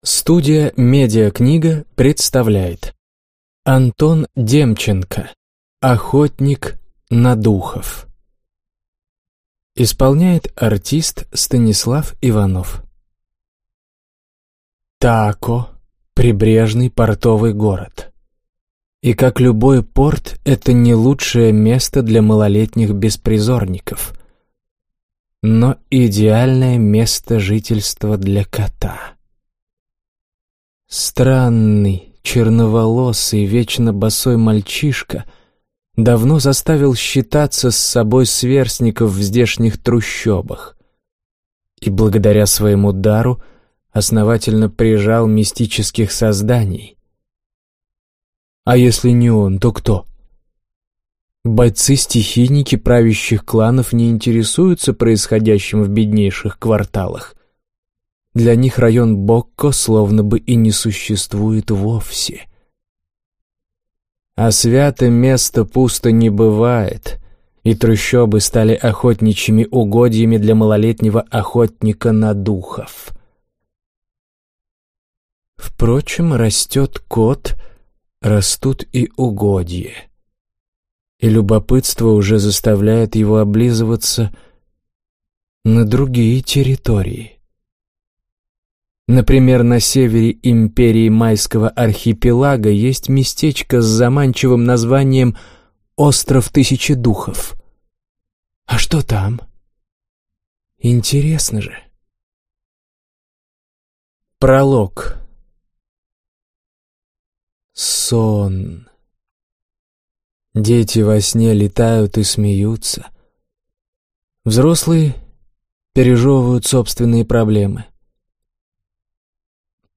Аудиокнига Охотник на духов | Библиотека аудиокниг
Прослушать и бесплатно скачать фрагмент аудиокниги